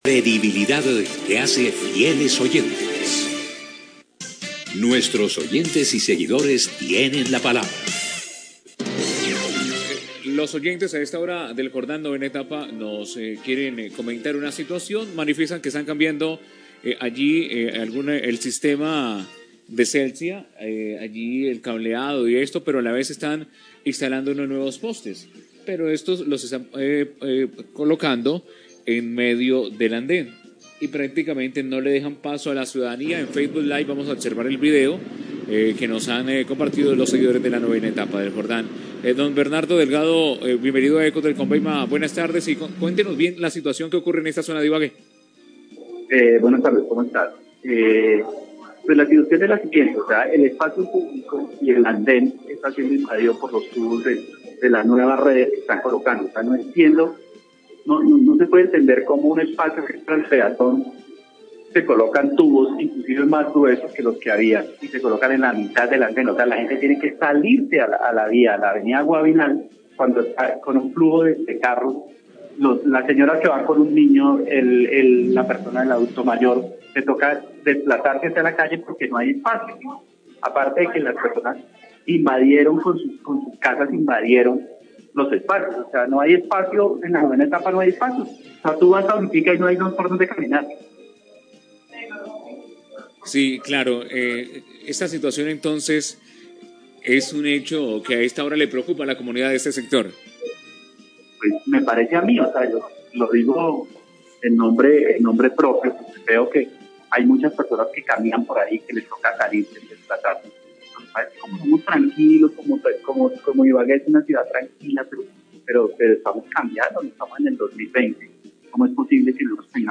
Radio
Ciudadano denuncia a través de Ecos del Combeima como se ha venido invadiendo el espacio público en la novena etapa del barrio El Jordán en Ibagué por los nuevos postes de Celsia, que según ellos están siendo puestos en la mitad del andén, sumado a que estos cambios están generando caos vehícular.